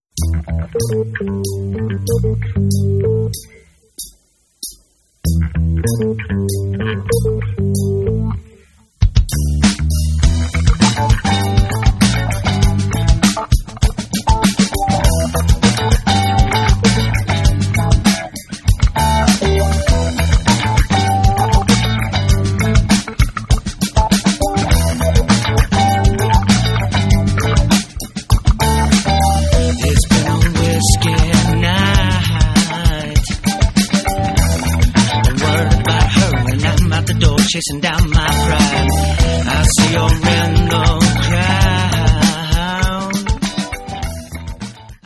Jamband
Rock